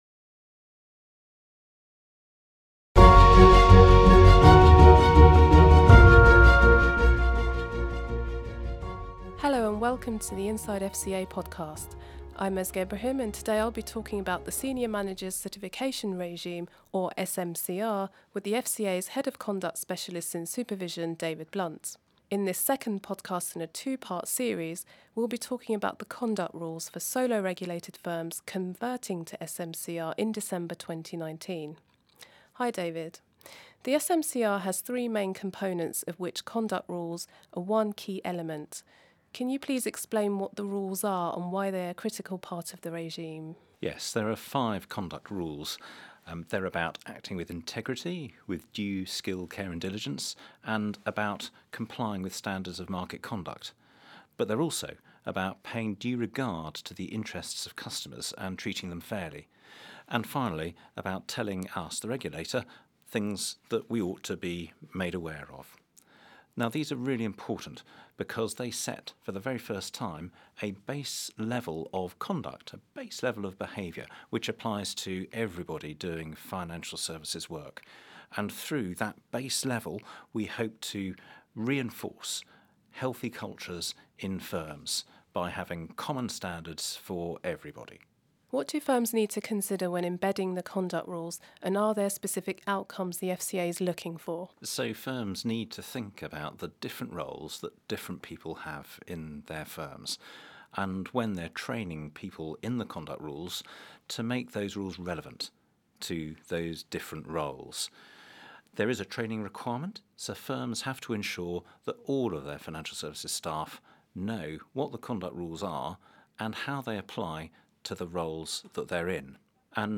Transcript: Inside FCA interview